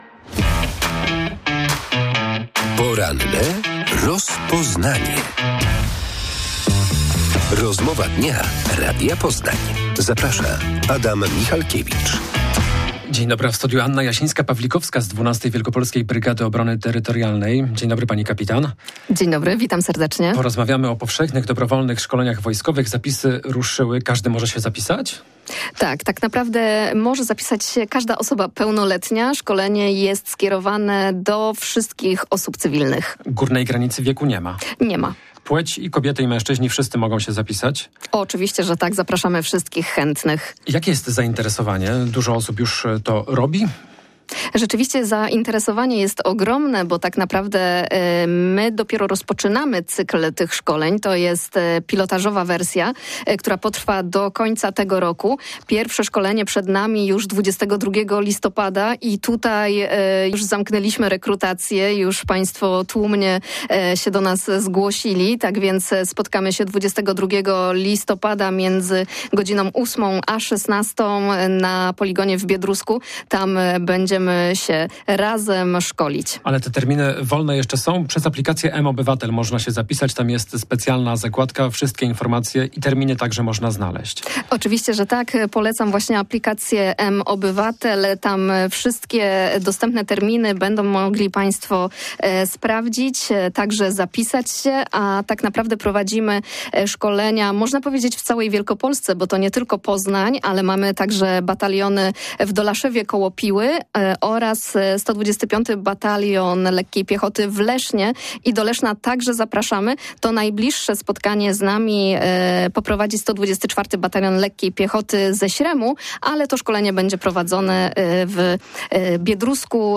W porannej rozmowie Radia Poznań